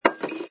sfx_put_down_bottle06.wav